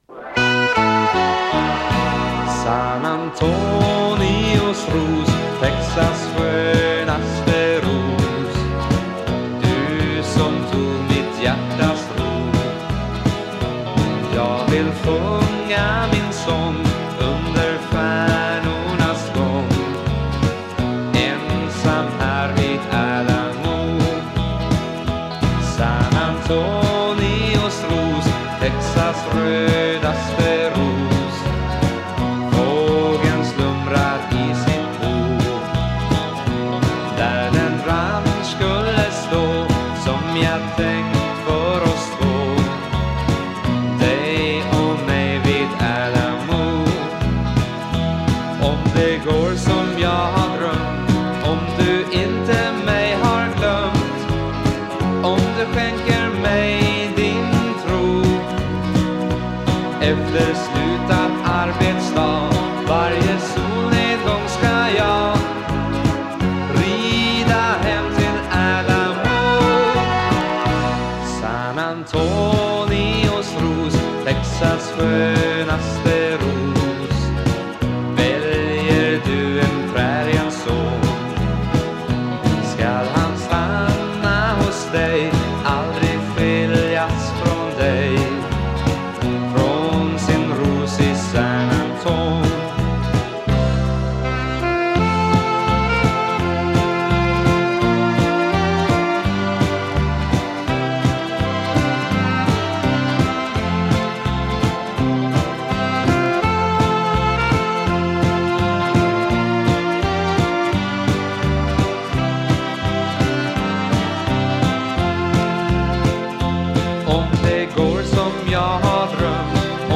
Vocals, Piano, Accordion
Bass
Vocals, Drums, Percussion
Vocals, Guitars
Sax